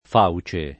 fauce [ f # u © e ]